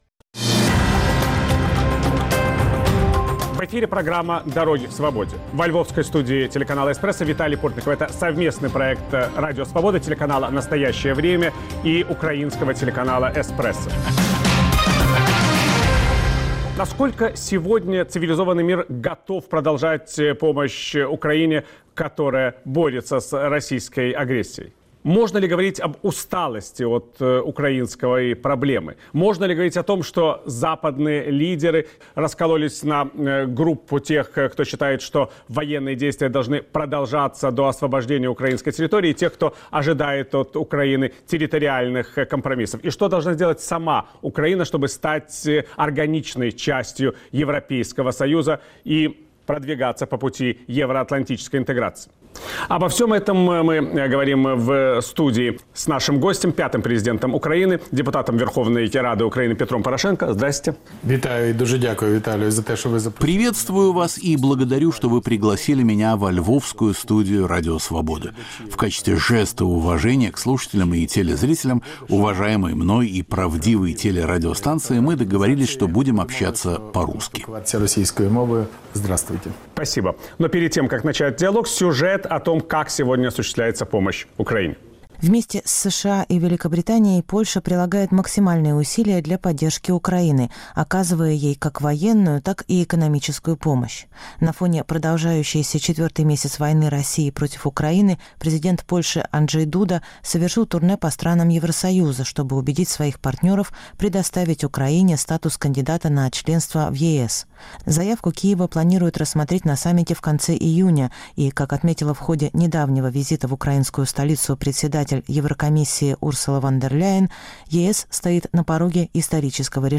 Виталий Портников расспрашивает пятого президента Украины, депутата Верховной Рады Петра Порошенко.